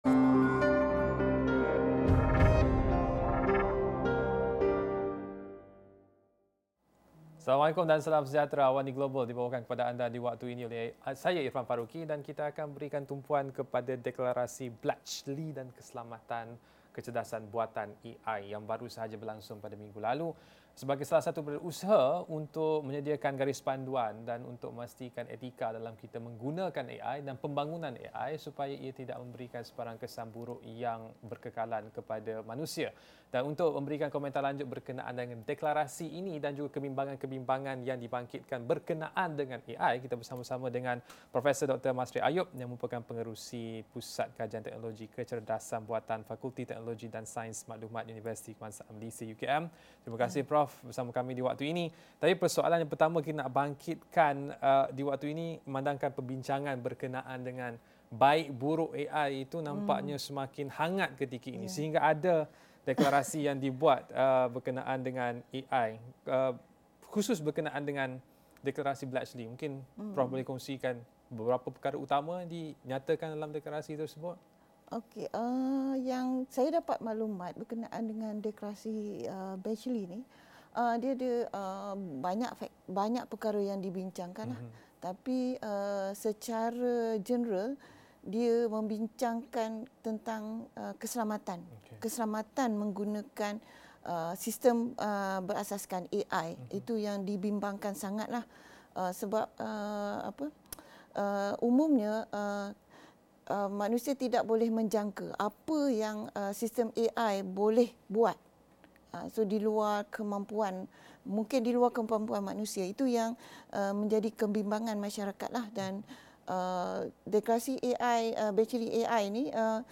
Apakah yang dimeterai dalam Sidang Kemuncak Keselamatan AI yang pertama? Bagaimana Malaysia berperanan menjadi peneraju kecerdasan buatan? Diskusi dan analisis AWANI Global malam ini.